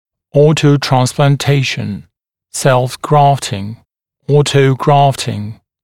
[ˌɔːtəuˌtrænsplɑːn’teɪʃn] [-ˌtrɑːn-], [self-‘grɑːftɪŋ], [ˌ’ɔːtəuˌgrɑːftɪŋ][ˌо:тоуˌтрэнспла:н’тэйшн] [-ˌтра:н-], [сэлф-‘гра:фтин], [‘о:тоуˌгра:фтин]аутотрансплантация